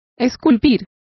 Complete with pronunciation of the translation of sculpture.